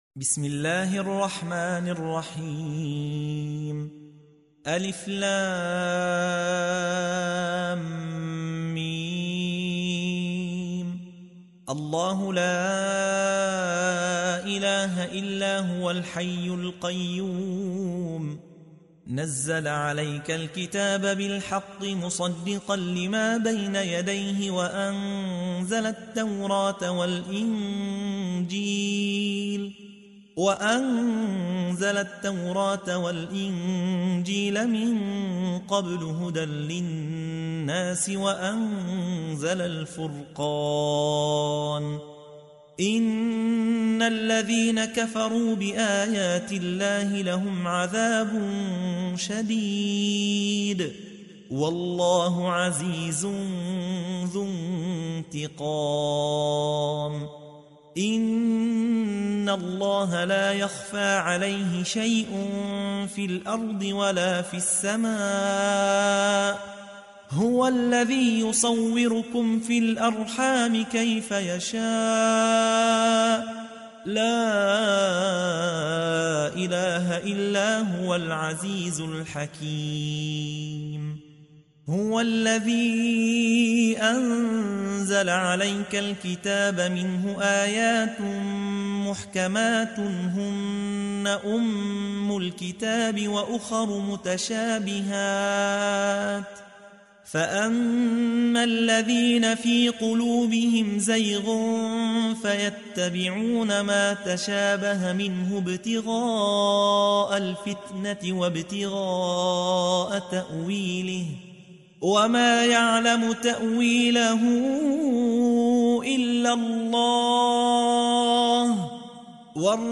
تحميل : 3. سورة آل عمران / القارئ يحيى حوا / القرآن الكريم / موقع يا حسين